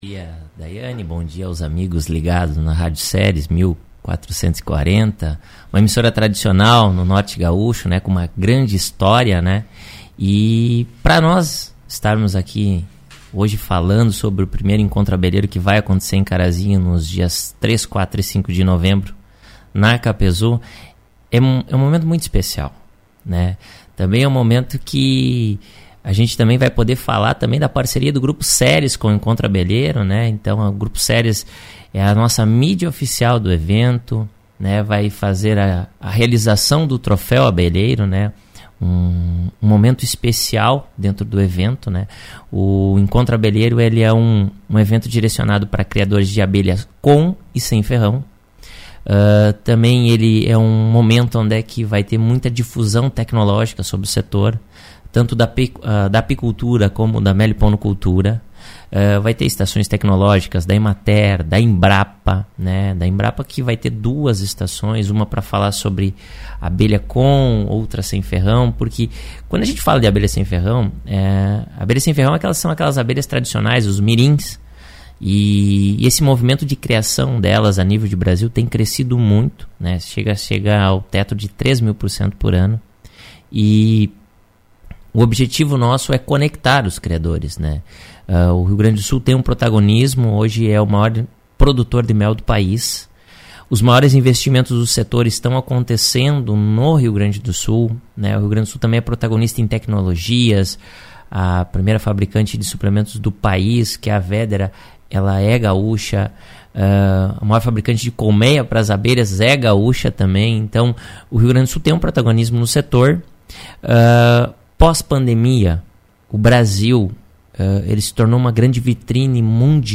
Em entrevista ao Grupo Ceres de Comunicação